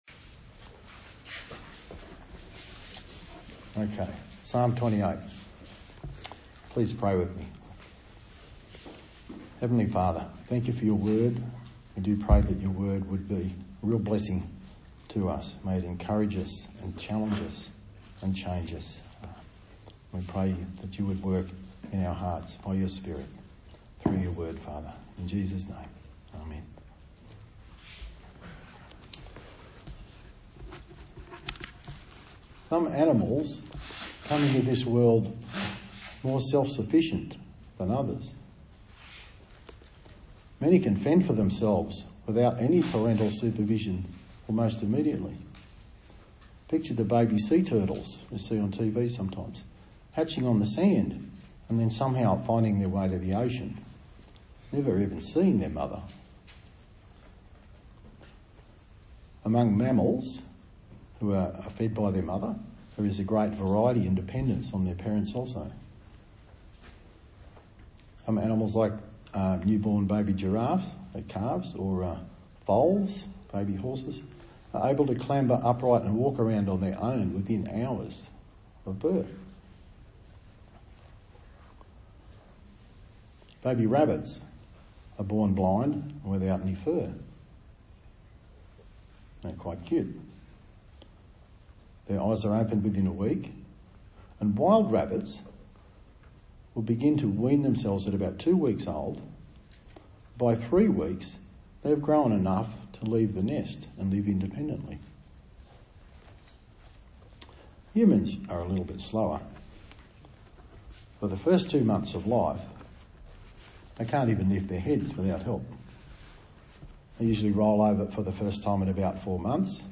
A sermon on the book of Psalms